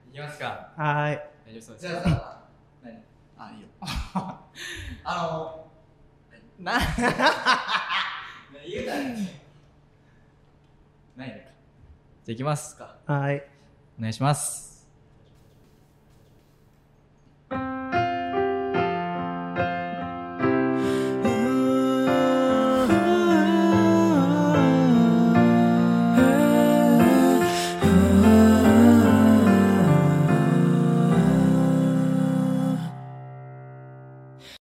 次々と歌うパートが変わって いくのに一音もずれずに 3人それぞれが完璧に歌いあげて 綺麗な素敵なハーモニーを 奏でてるの本当に凄い✨✨